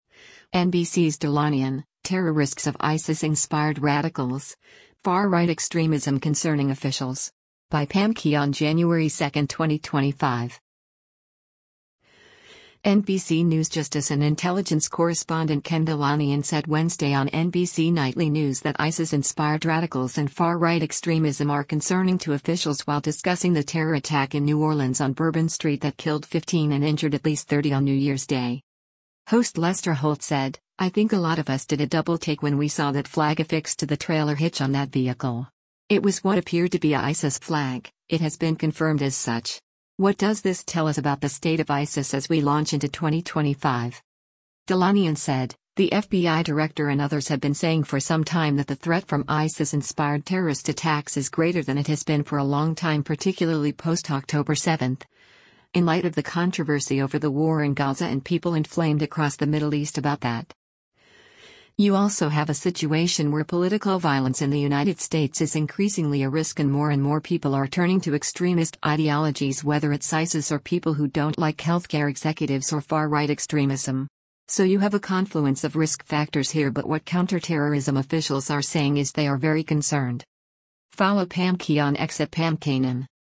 NBC News justice and intelligence correspondent Ken Dilanian said Wednesday on “NBC Nightly News” that ISIS-inspired radicals and far-right extremism are concerning to officials while discussing the terror attack in New Orleans on Bourbon Street that killed 15 and injured at least 30 on New Year’s Day.